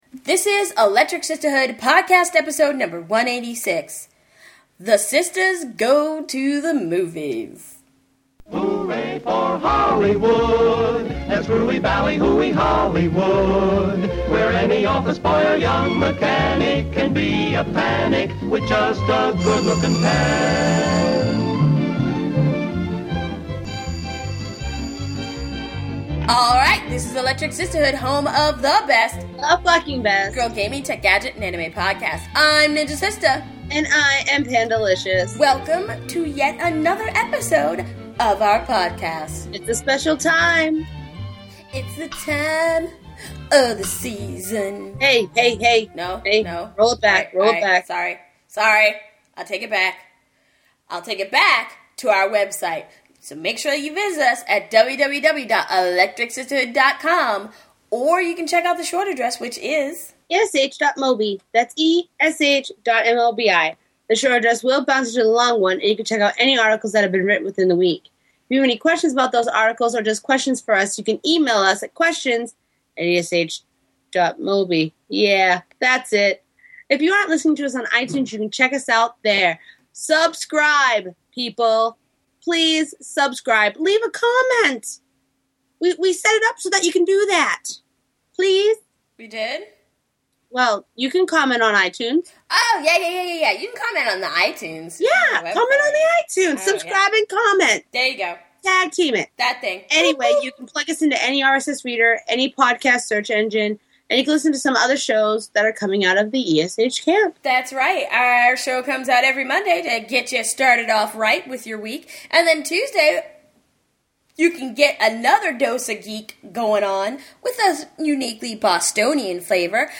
A new week, a new episode of the ElectricSistaHood Podcast, and this one is a clip show in prep for the upcoming Motion Picture Awards on Sunday.
This episode of the podcast contains clips of the ladies talking about various movies over the past year which we thought would be a great way to ramp up to the Oscars.